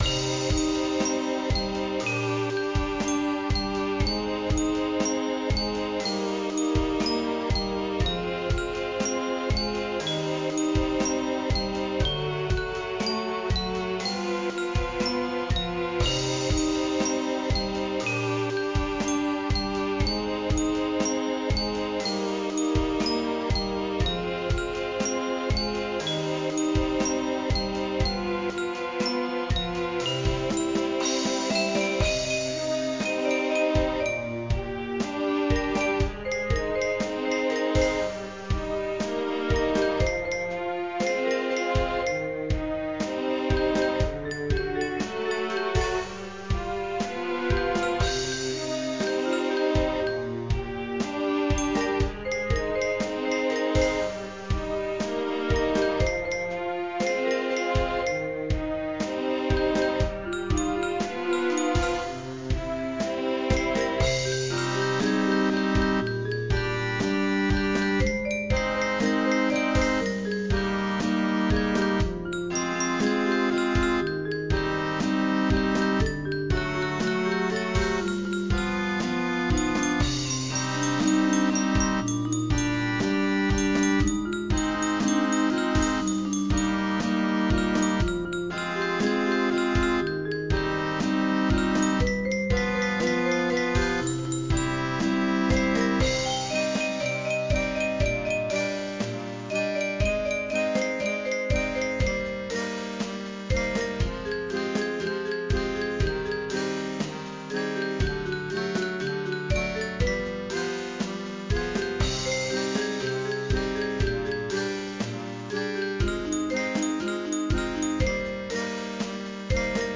インスト曲